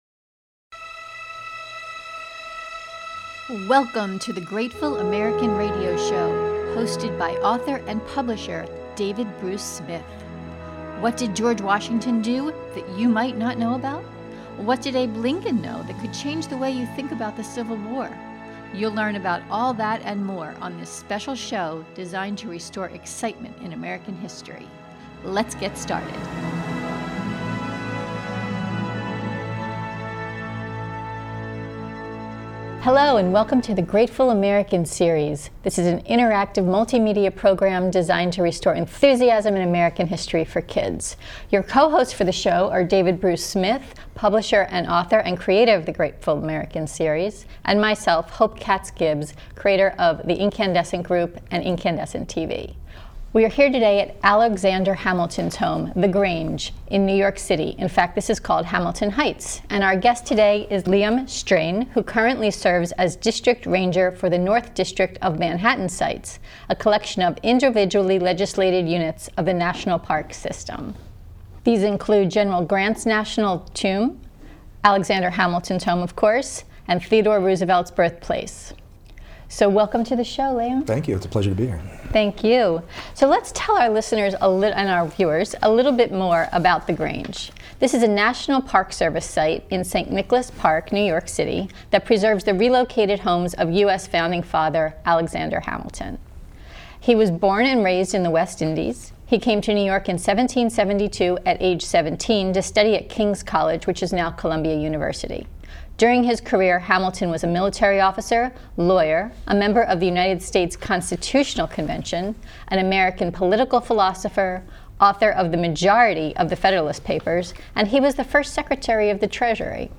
On the Inkandesent Kids Show, we take it one step further with interviews by kids, for kids.